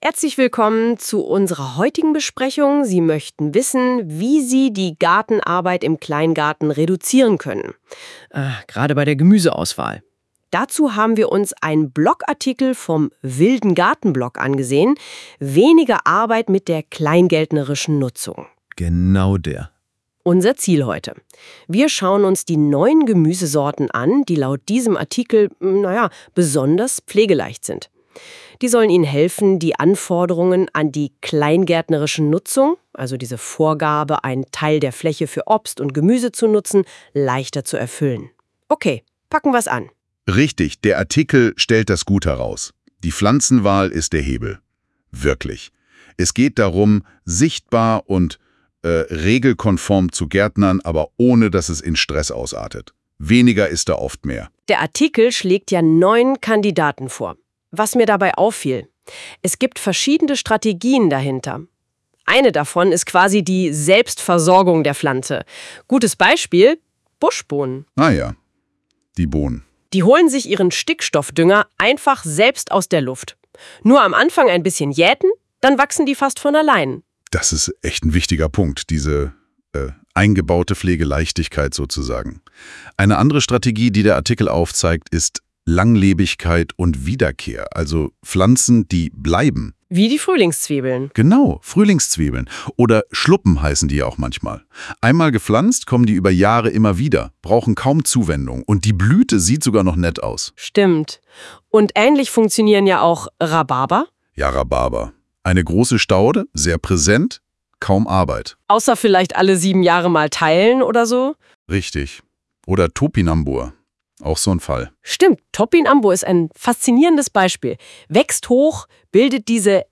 Mit über vier Minuten Podcast-Länge haben sie (die KI-Specher) die Zeitvorgabe leider nicht geschafft, aber das Gemüse ist tatsächlich Hauptthema. Sie haben sogar Kategorien für die Gemüsearten gebildet: langlebig, wiederkehrend, selbstversorgend…